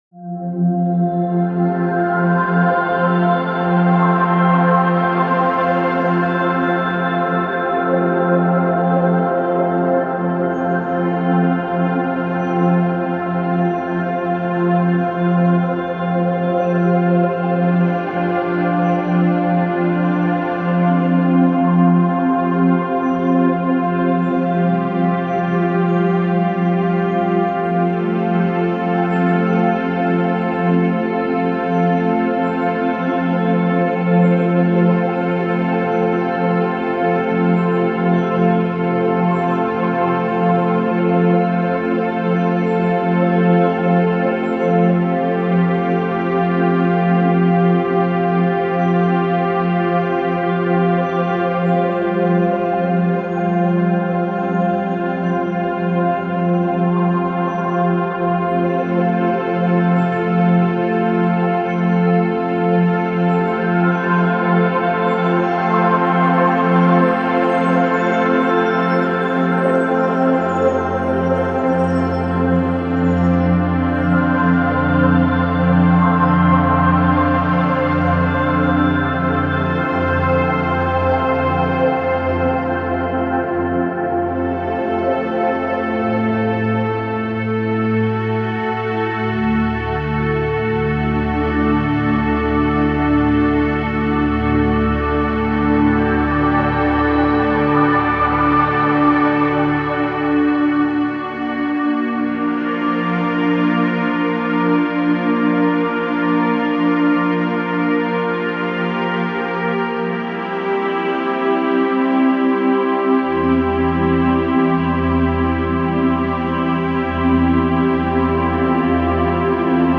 lagoon1.opus